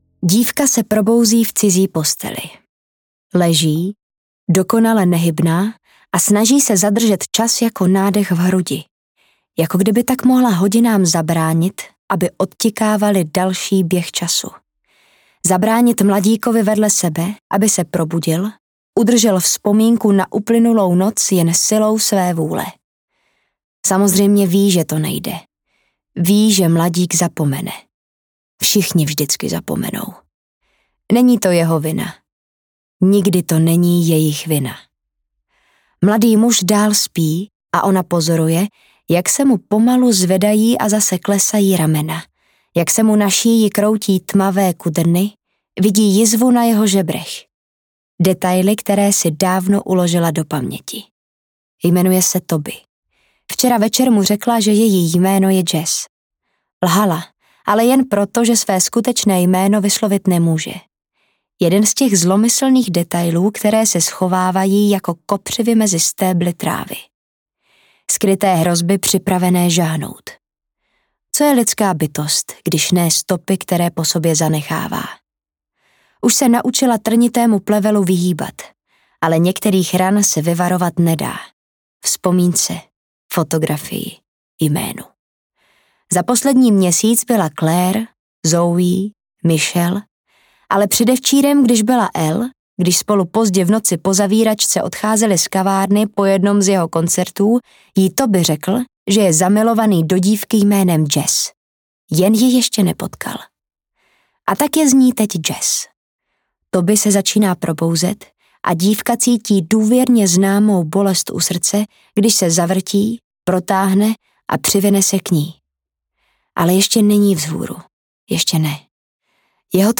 Neviditelný život Addie LaRue audiokniha
Ukázka z knihy